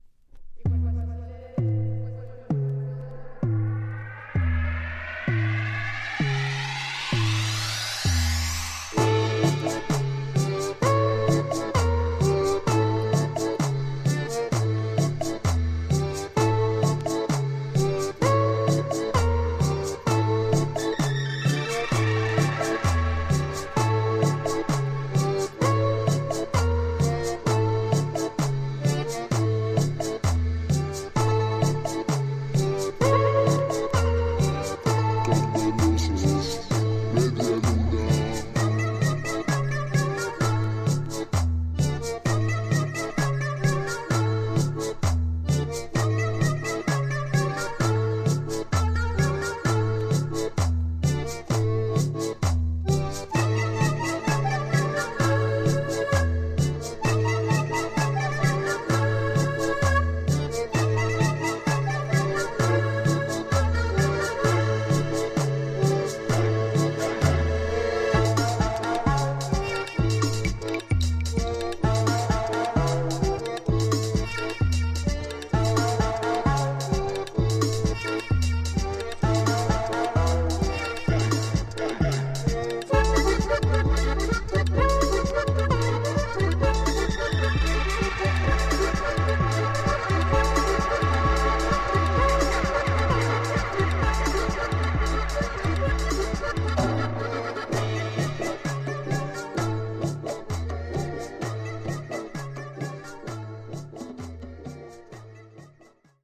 Tags: Cumbia , Japan , Colombia